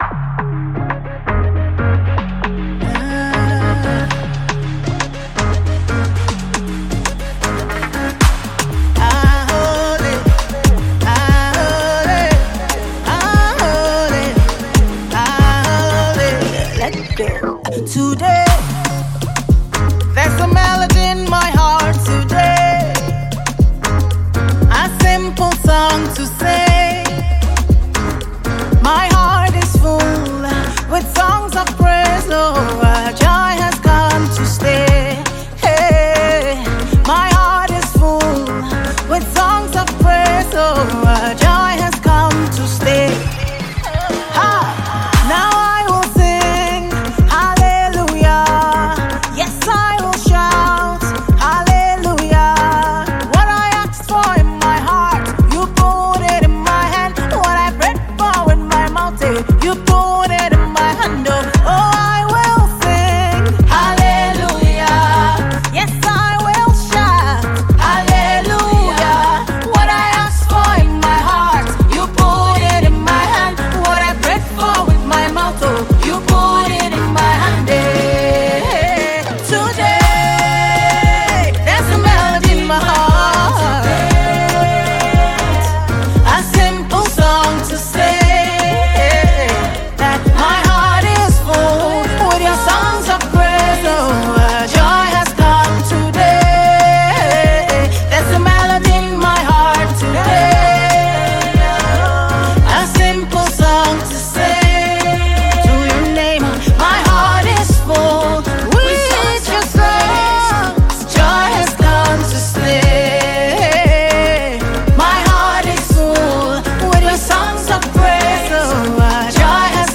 Mp3 Gospel Songs
Female Nigerian gospel songstress
One of the Nigerian female gospel singers